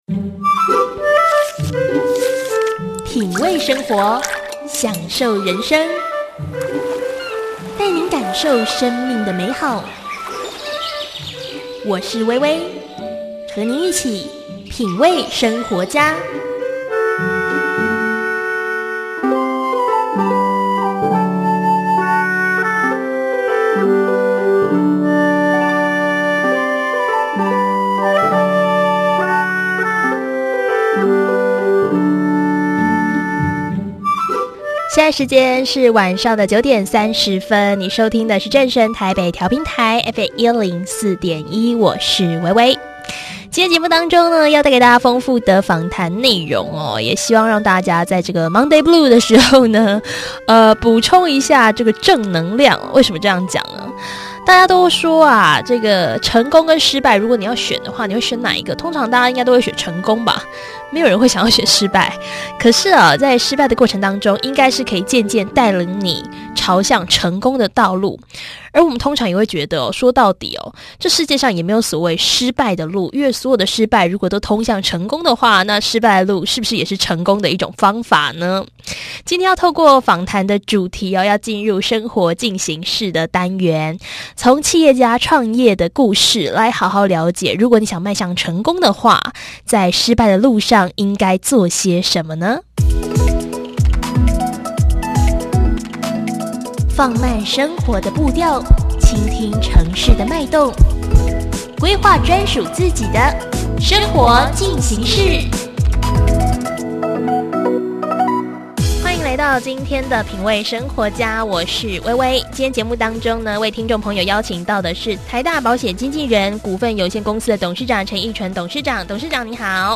受訪者